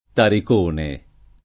[ tarik 1 ne ]